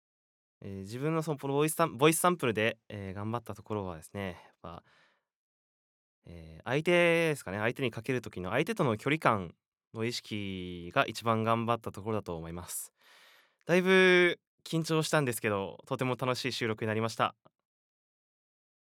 ボイスサンプル
フリートーク